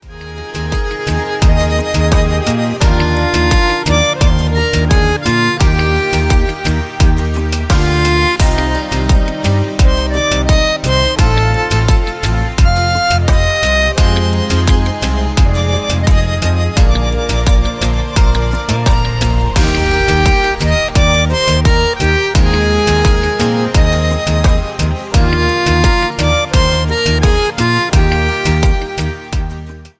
KIZOMBA  (03.27)